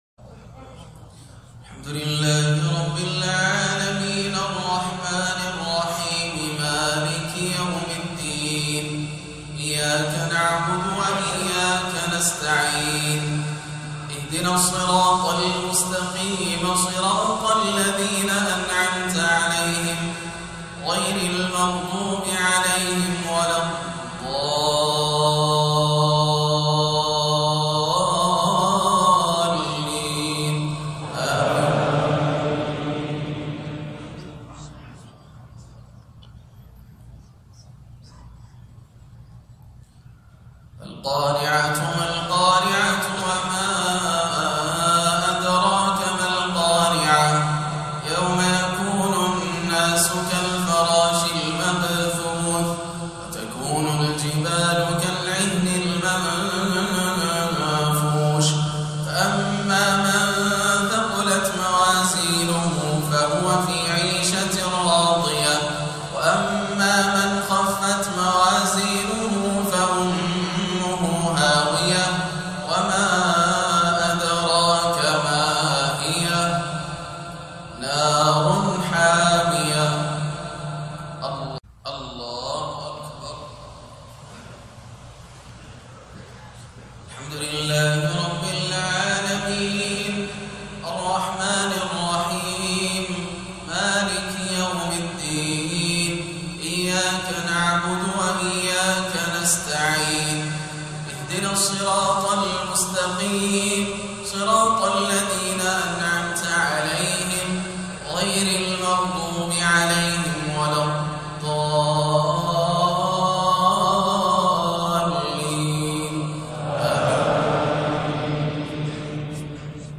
صلاة الجمعة 2-1-1439هـ سورتي القارعة و التكاثر > عام 1439 > الفروض - تلاوات ياسر الدوسري